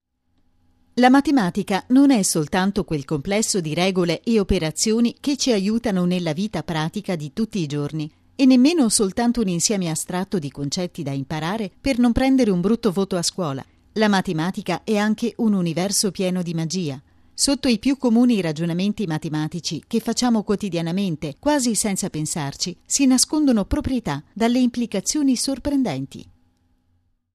voce versatile, calda, istituzionale, ironica
Sprechprobe: eLearning (Muttersprache):